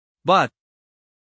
but-stop-us-male.mp3